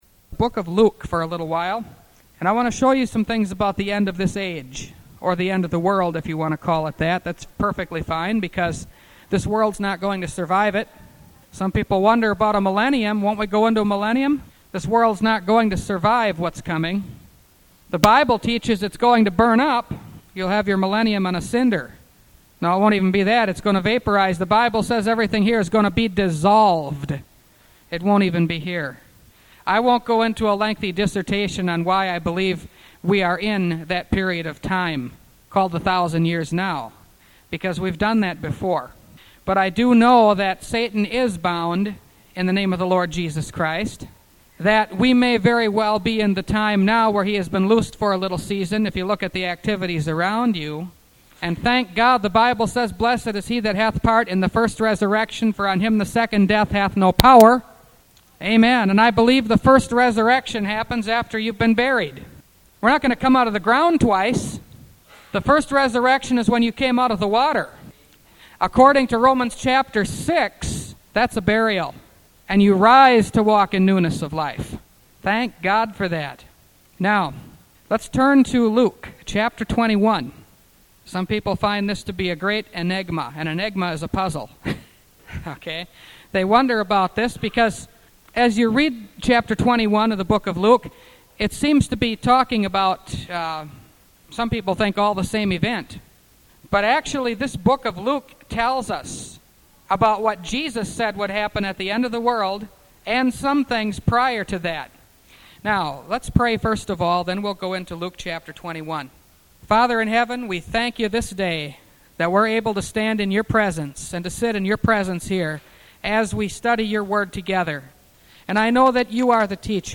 Revelation Series – Part 27 – Last Trumpet Ministries – Truth Tabernacle – Sermon Library